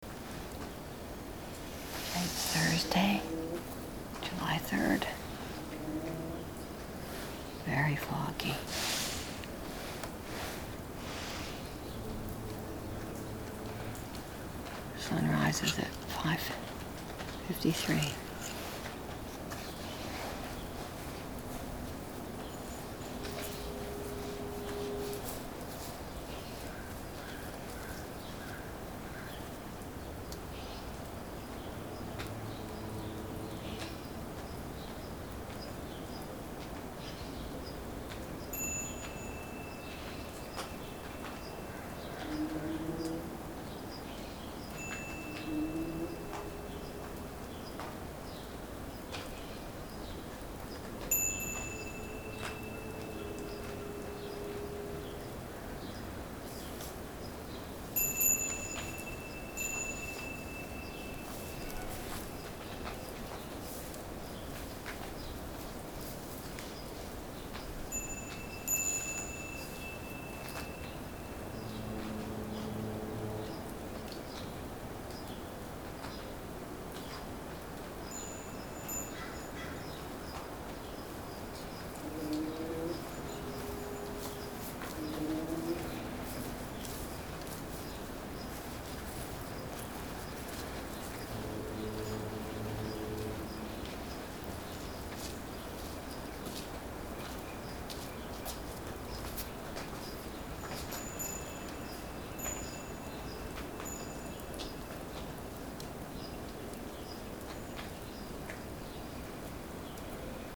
Dripping. The trees were dripping fog.